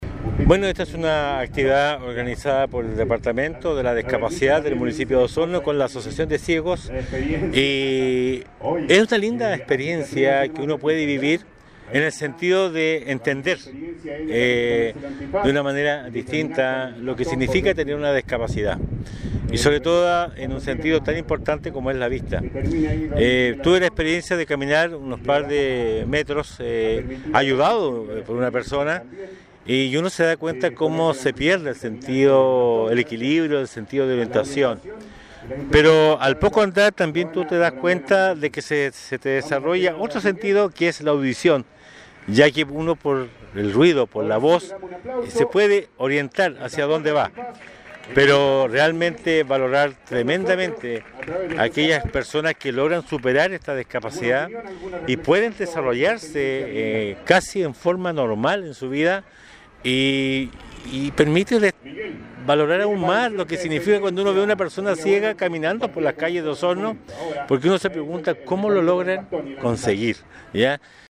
Por su parte, el alcalde de Osorno Emeterio Carrillo, manifestó que admiraba el esfuerzo de las personas con discapacidad visual para superar las dificultades derivadas de su problemática.